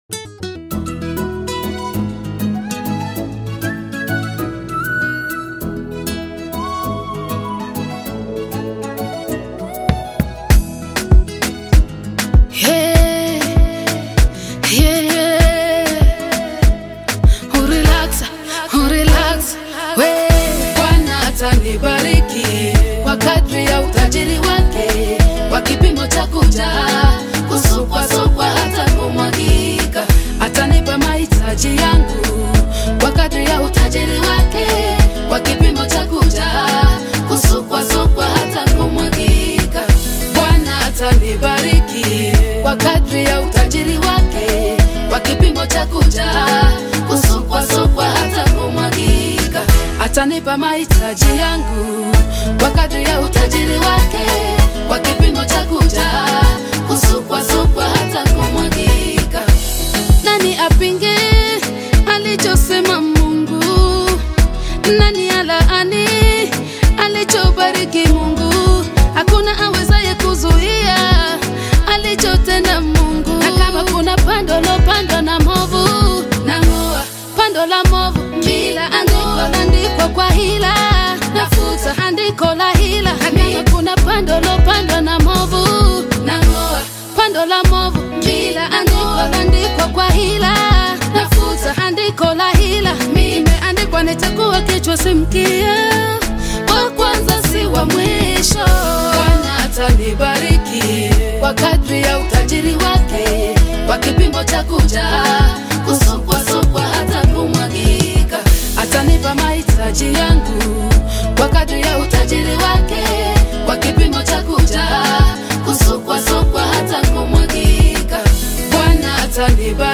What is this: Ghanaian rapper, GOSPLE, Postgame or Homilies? GOSPLE